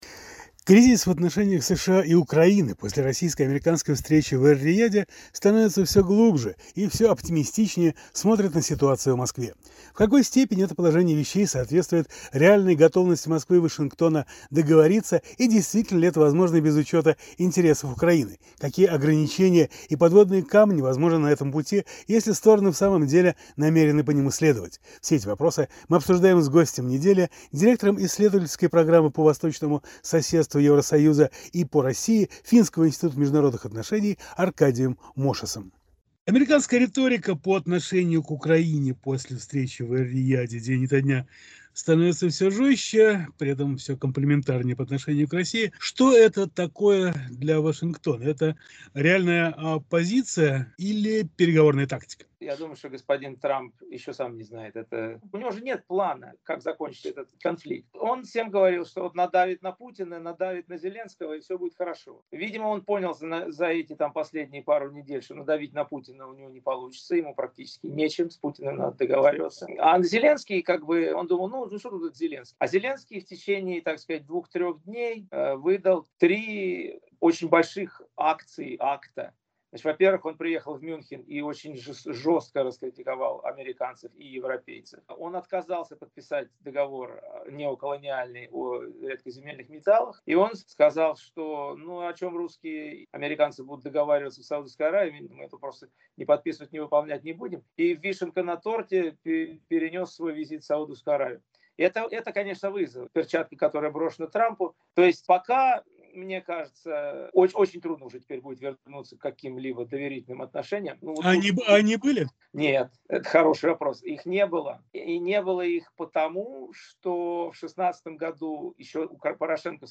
Гость недели – политолог